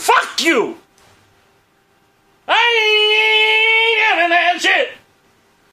FailSound.mp3